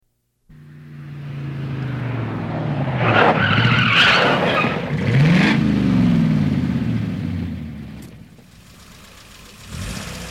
دانلود آهنگ سه بعدی 5 از افکت صوتی طبیعت و محیط
جلوه های صوتی